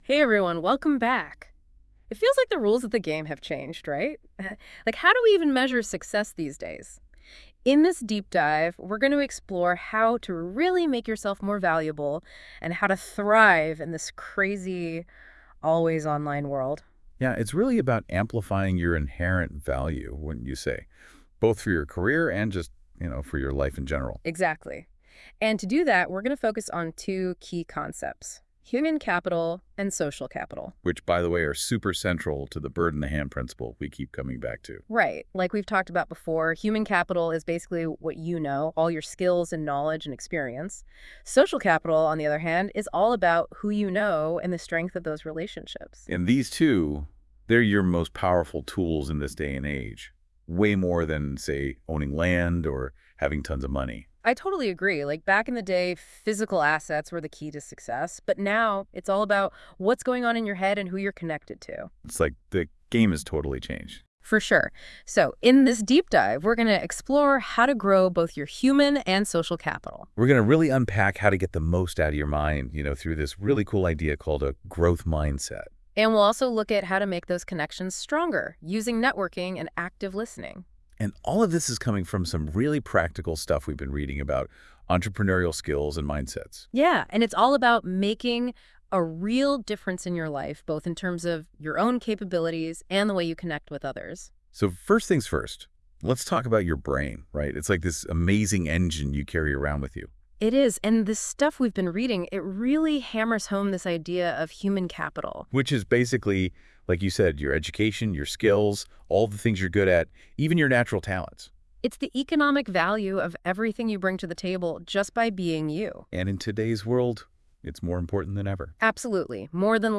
Episode 8 - AI Talk Show Week 6 - TorontoMet Entrepreneur Institute, Turning Ideas Into Reality - Located in Toronto, ON